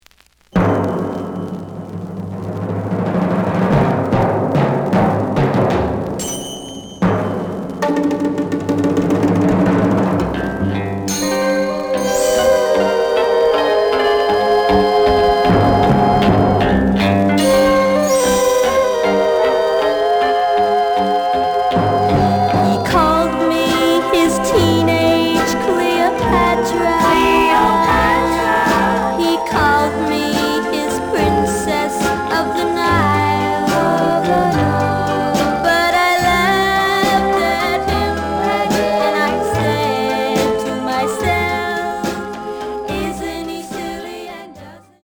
The audio sample is recorded from the actual item.
●Genre: Rock / Pop
Slight noise on beginning of A side.